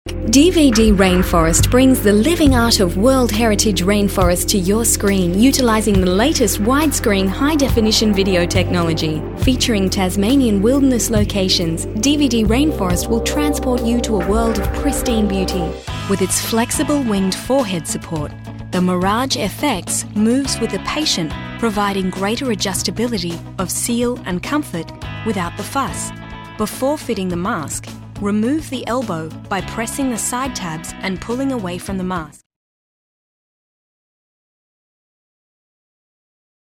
Female Voice Artist with RMK Voices Sydney